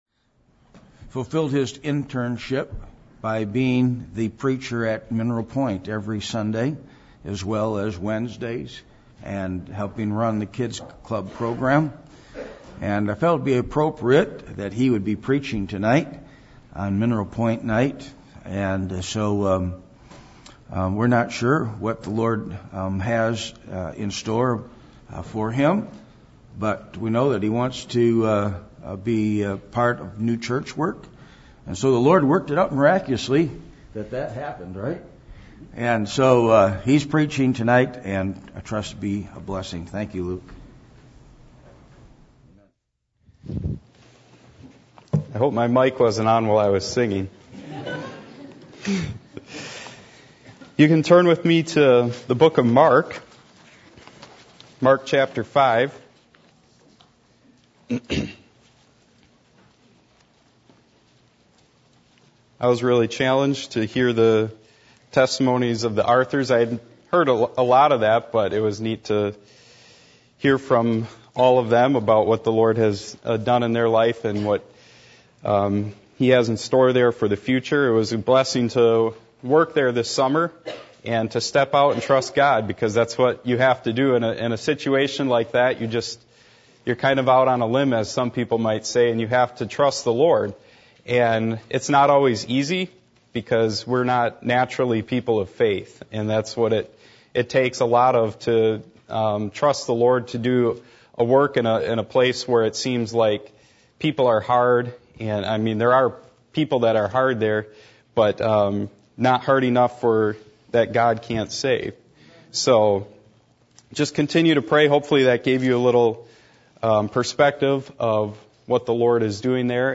Mark 5:18-20 Service Type: Missions Conference %todo_render% « Moved With Compassion Are You Being Setup?